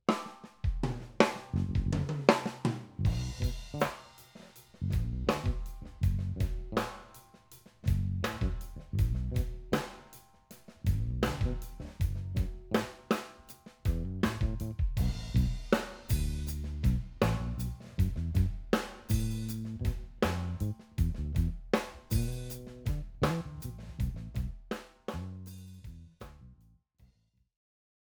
Verbtone – Physical modeled plate reverb – Smooth and deep
Drums_Room
Verbtone_Drums_Room.wav